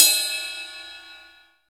Index of /90_sSampleCDs/Roland L-CDX-01/CYM_Rides 1/CYM_Ride menu